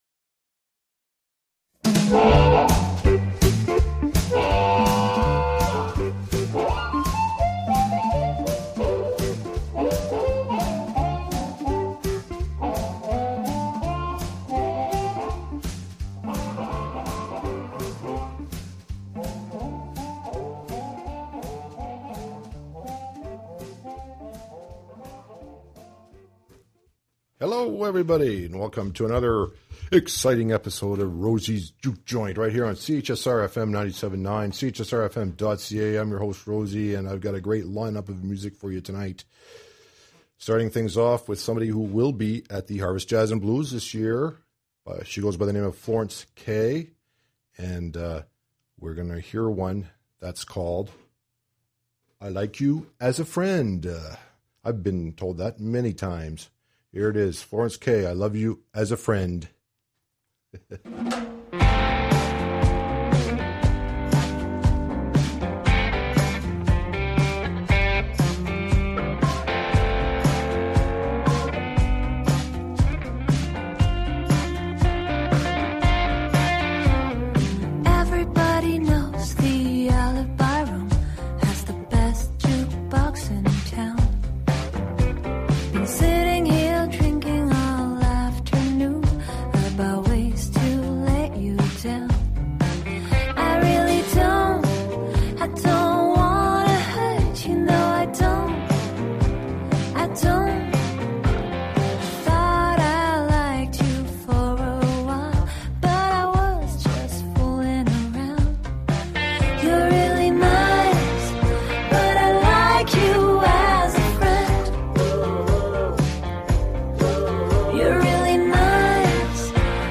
Rosie's Juke Joint -Blues & Roots